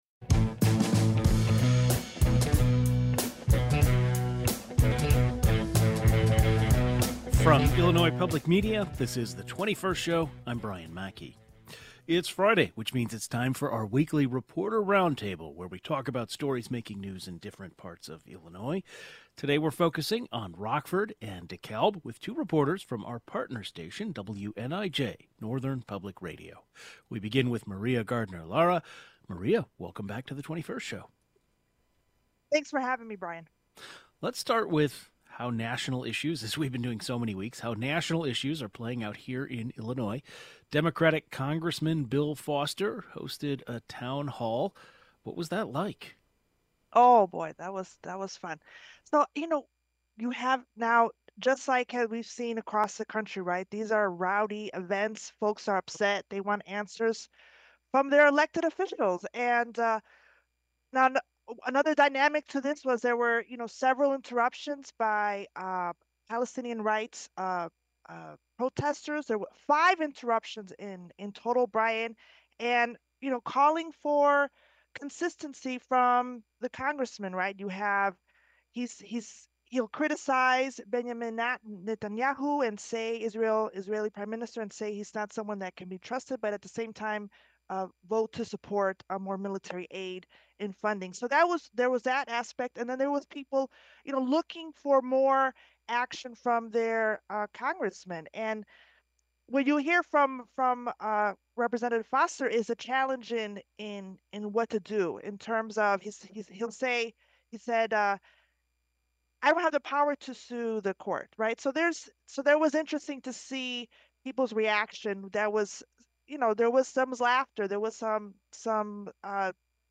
Two public radio journalists join the conversation.